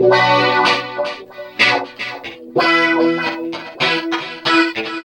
69 GTR 1  -R.wav